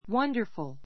wʌ́ndə r fəl